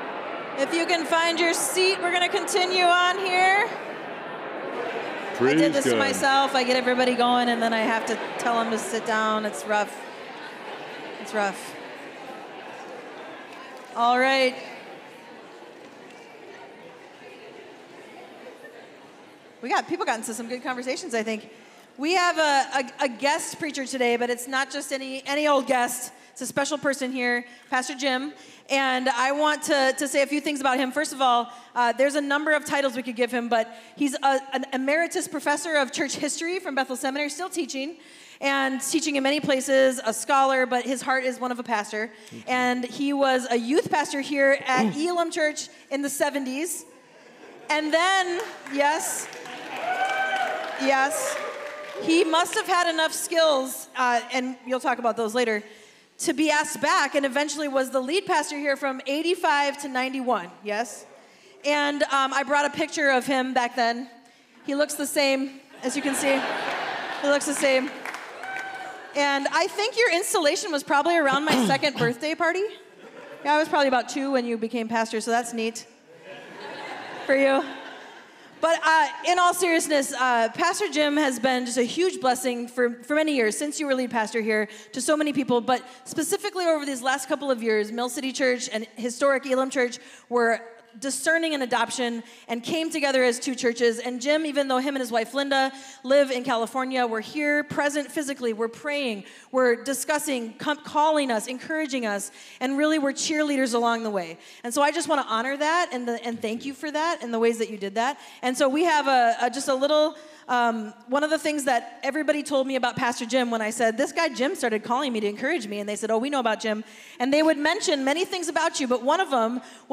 Mill City Church Sermons Relating: The Lord is My Light Jun 03 2024 | 00:42:10 1x 00:00 / 00:42:10 Subscribe Share RSS Feed Share Link Embed